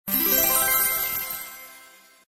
minislot_win_small.mp3